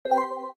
cam_snap_3.ogg